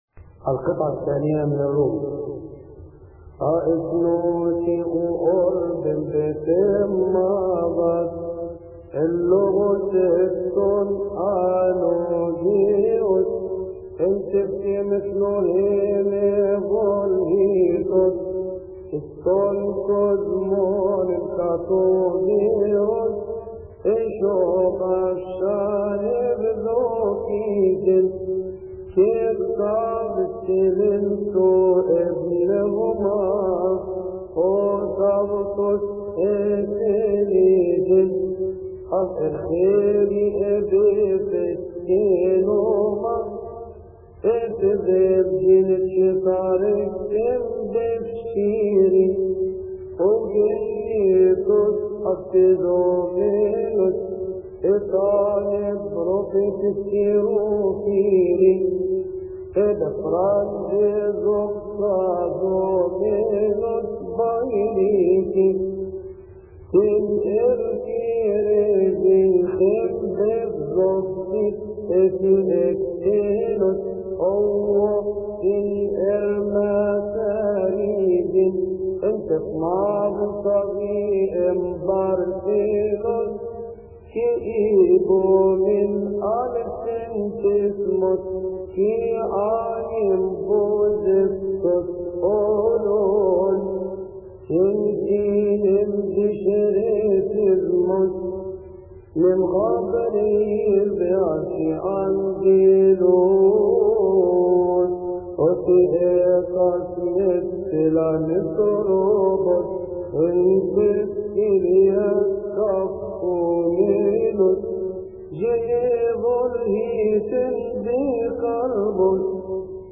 يصلي في تسبحة عشية أحاد شهر كيهك للمرتل